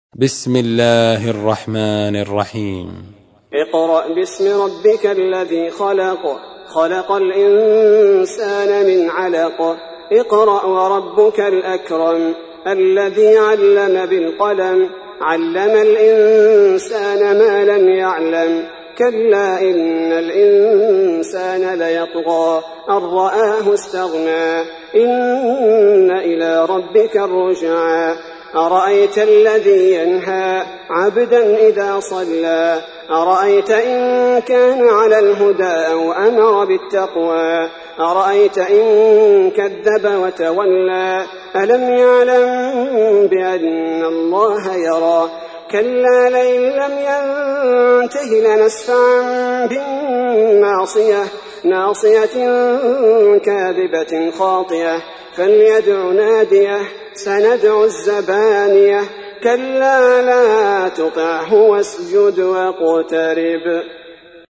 تحميل : 96. سورة العلق / القارئ عبد البارئ الثبيتي / القرآن الكريم / موقع يا حسين